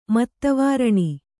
♪ matta vāraṇi